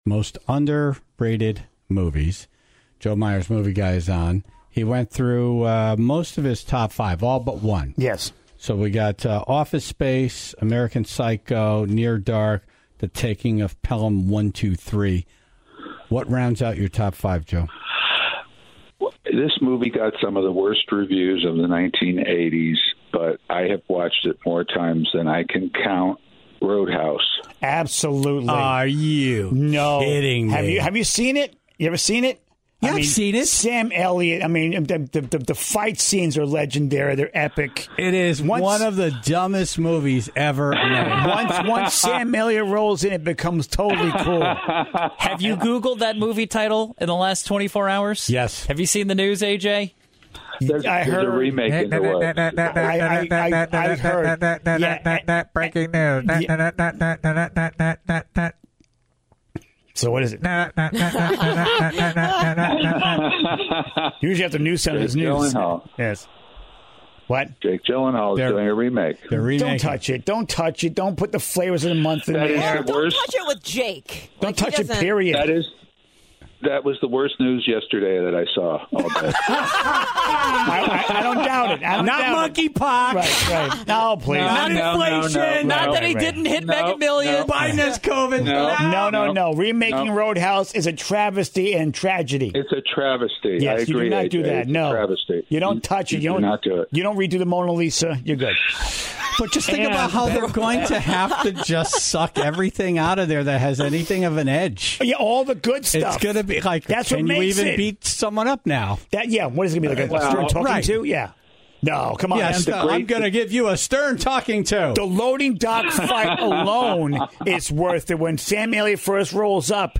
on the phone this morning